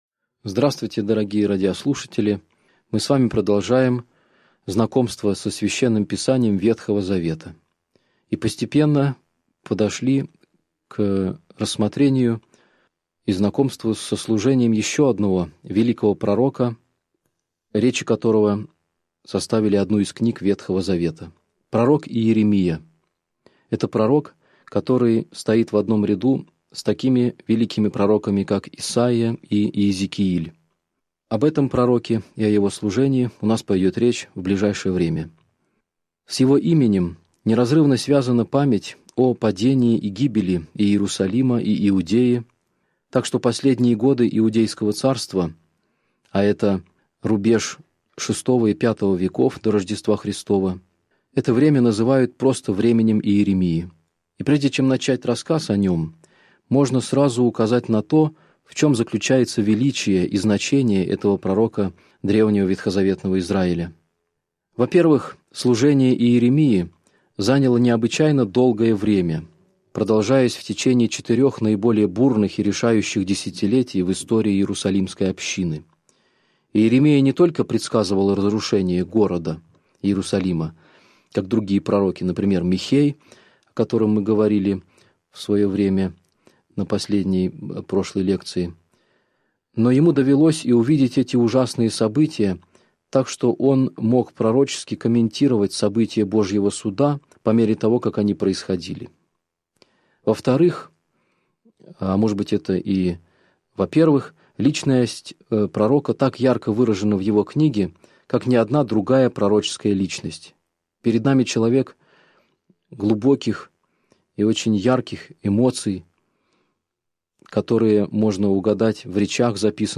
Аудиокнига Лекция 17. Пророк Иеремия | Библиотека аудиокниг